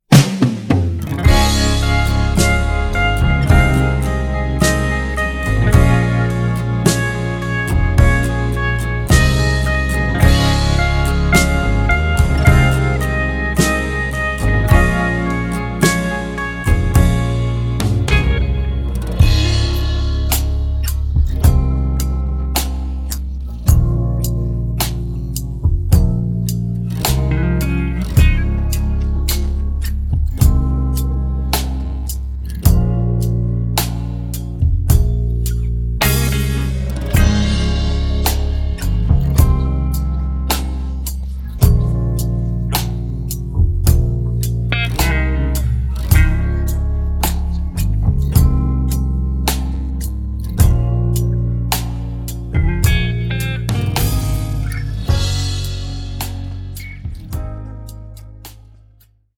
음정 -1키 4:35
장르 가요 구분 Voice Cut